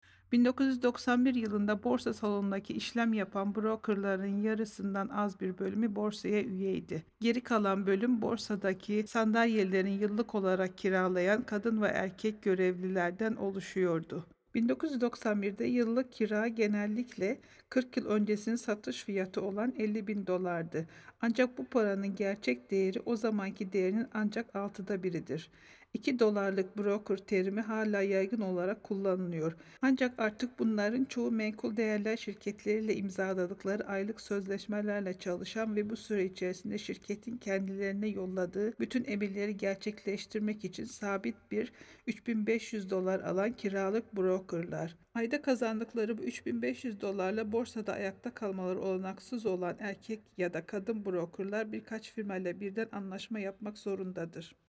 Turkish voice over
Sprechprobe: Sonstiges (Muttersprache):
I have worked for many years to develop my crisp strong toned voice and speak with clear diction and a versatility to adjust to each individual project.